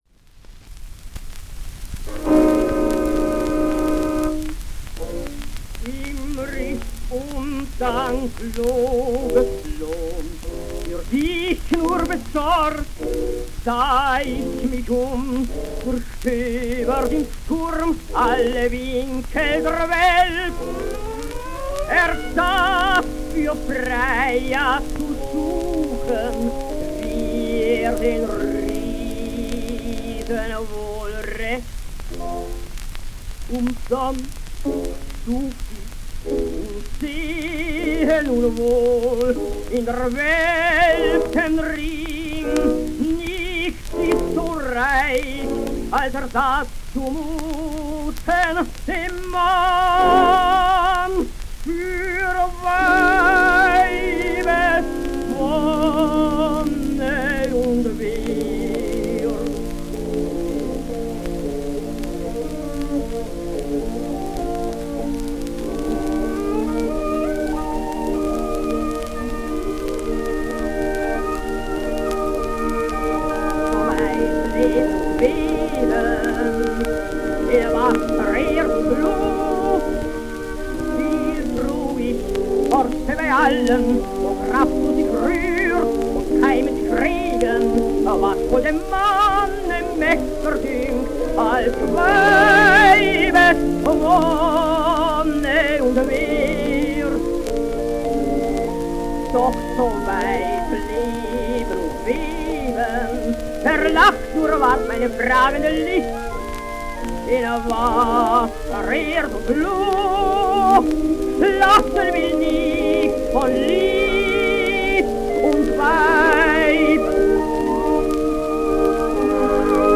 Hans Bechstein singsRheingold: